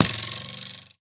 bowhit4.ogg